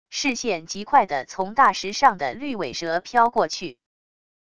视线极快地从大石上的绿尾蛇飘过去wav音频生成系统WAV Audio Player